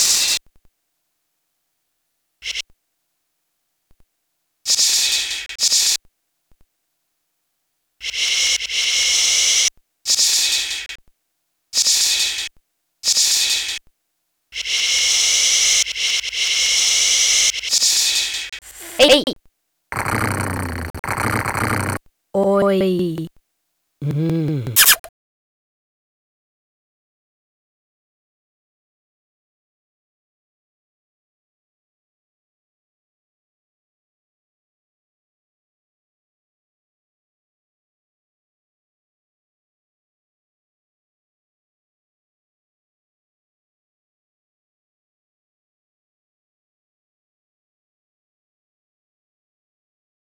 phonetic typewriter intermezzo
sehr gut finde ich die beschränkung auf wenige sparsam eingesetzte elemente. steigern liesse sich die sache durch straffung und den differenzierteren einsatz von pausen (jedesmal auf die spacetaste gedrückt verlängert die pause). besonder bei sekunde 20, vor dem neuen soundpartikel, sollte ein pause spannung schaffen, um die folgende wendung vorzubereiten.